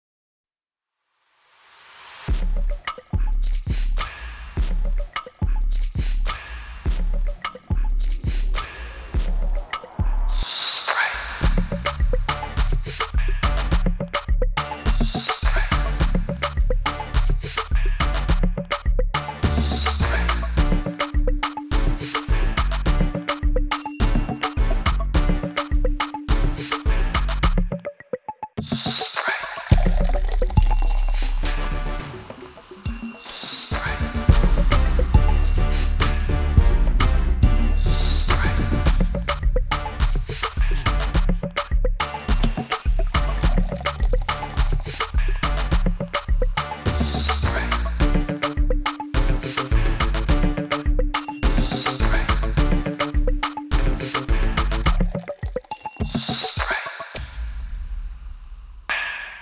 Instrumental only